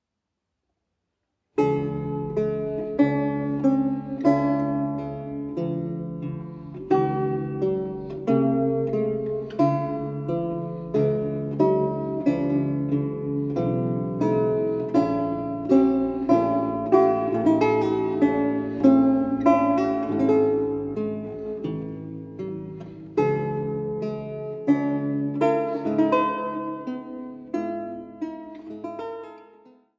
Laute